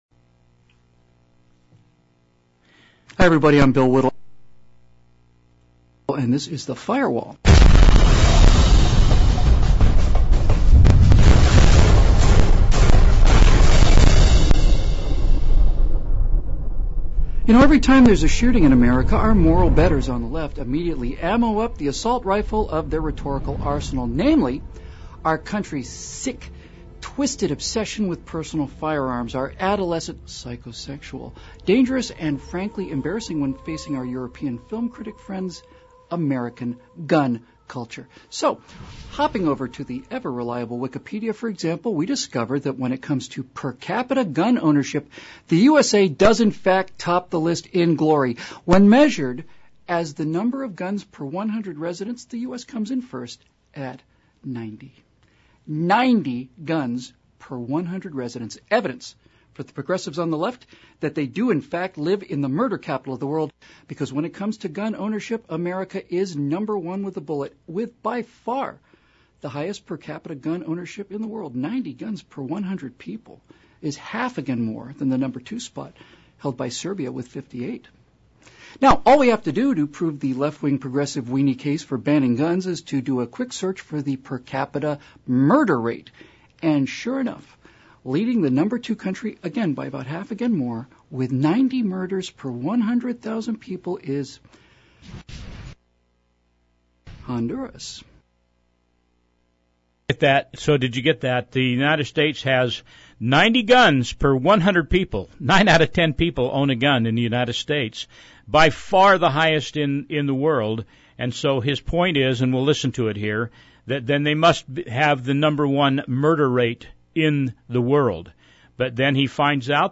Originally played on ktalk radio, this is a short (about 7.5 minutes) and factual report on the study of gun control & resultant violence showing that more guns actually yields less violence.